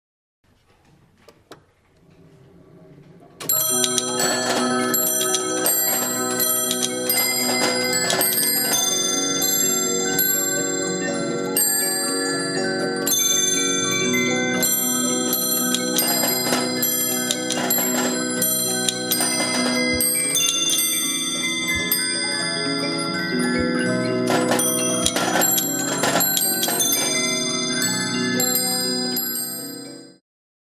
Boîte à musique de type cartel fabriquée par Heller
Unique en France par la taille de son cylindre de 54,5cm. 12 airs à suivre.
Clavier musical de 124 lames
9 carillons
1 tambour et 1 castagnette.
Remontage d'un ressort par manivelle latérale, les picots du cylindre relèvent les lamelles du clavier pour former la mélodie, mais peuvent actionner aussi les carillons et percussions.